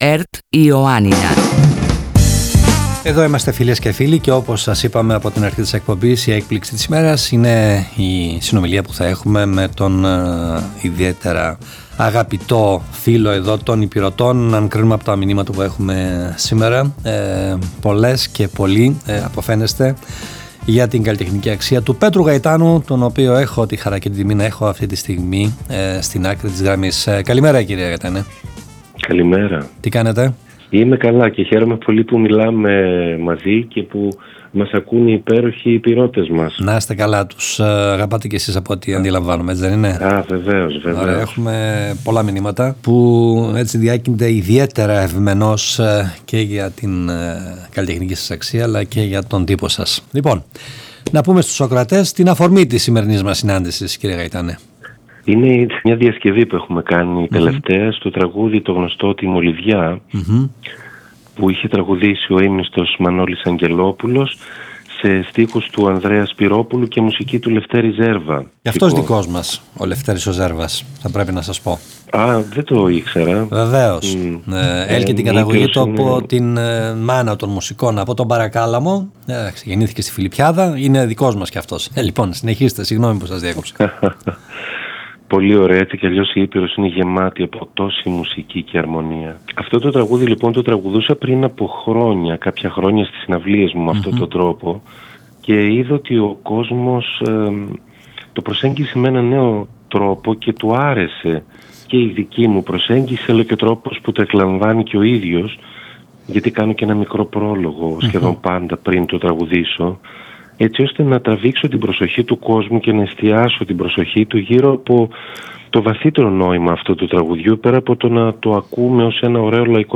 Στην συνέντευξη που παραχώρησε στον Περιφερειακό Σταθμό Ιωαννίνων ο Πέτρος Γαϊτάνος μίλησε για το καθηλωτικό αυτό τραγούδι, για το βαθύτερο νόημα που κρύβουν οι στίχοι ενώ αναφέρθηκε και στα υπόλοιπα καλλιτεχνικά του σχέδια.